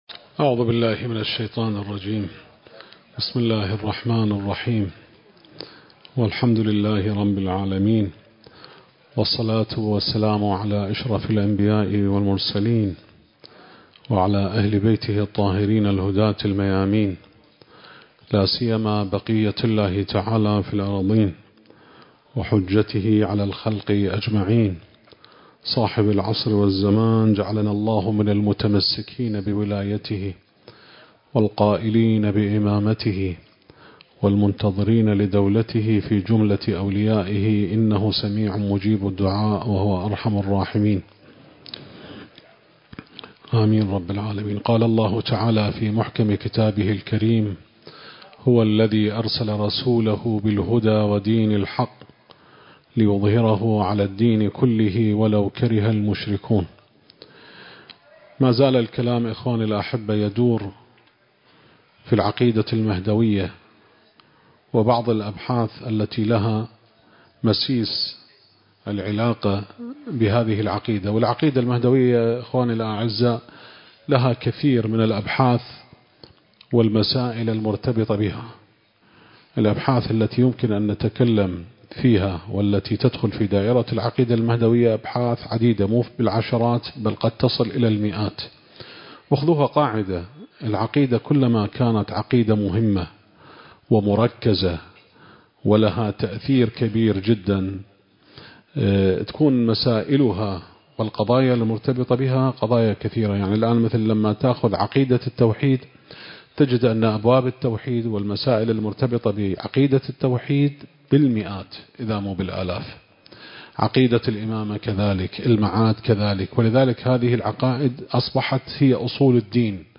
المكان: جامع وحسينية أهل البيت (عليهم السلام) / بغداد التاريخ: 2025